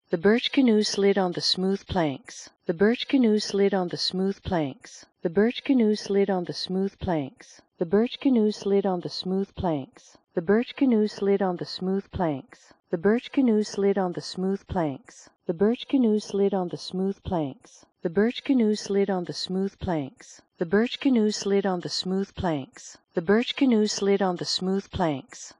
The HRTF filters are applied to the left channel only.
After compression and decompression
by the VLC HQ 3D 48 codec at 96000 bps
reference_female_2_vlc_v7_hq_3d_48.wav